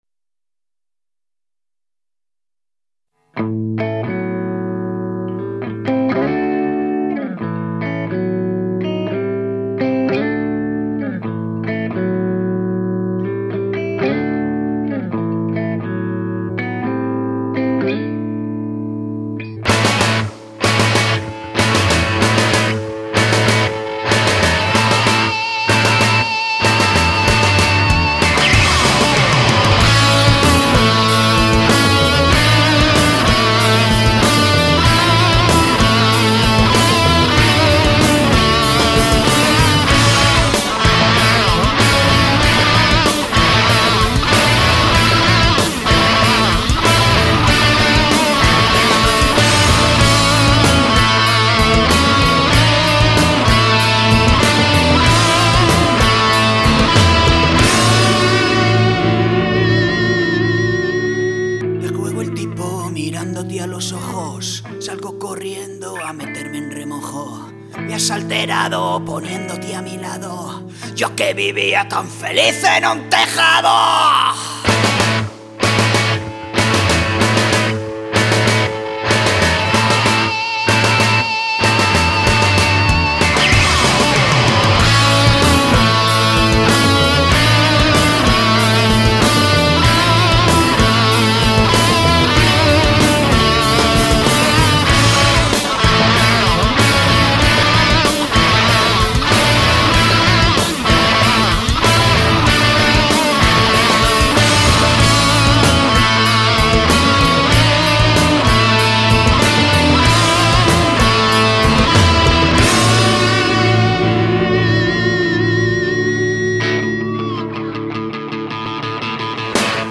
Canciones con voz recientitas!!